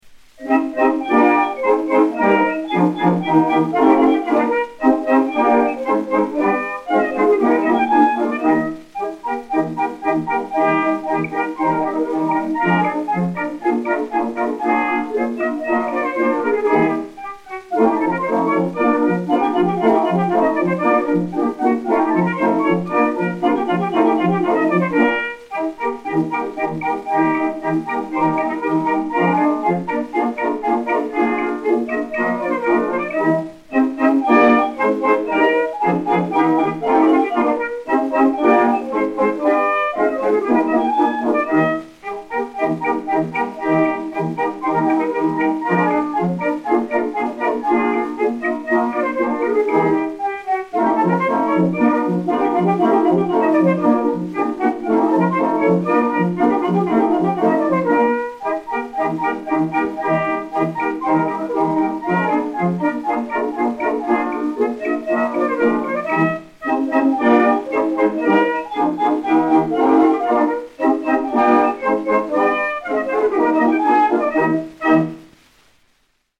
l'Œil crevé, quadrille (Isaac Strauss, d'après Hervé), répertoire des Bals de l'Opéra
Zonophone X 80637 et X 80638, mat. 6214h, enr. vers 1910